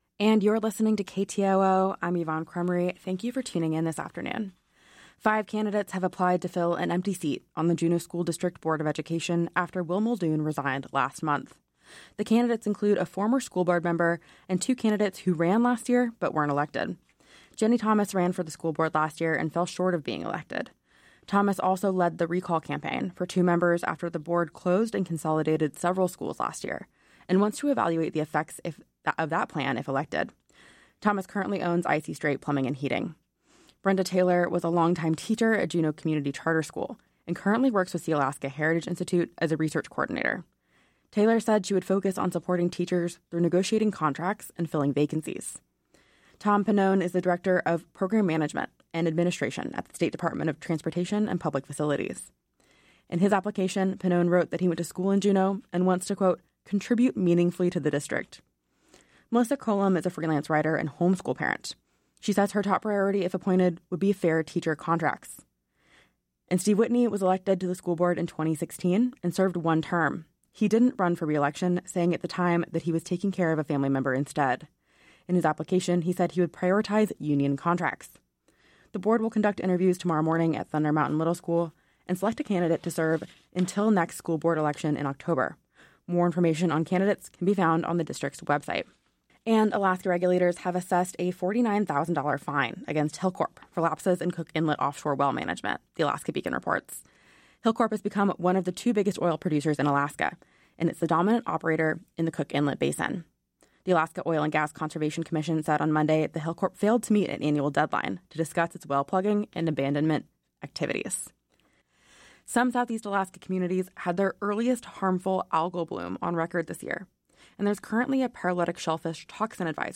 Newscast – Friday, May 16, 2025 - Areyoupop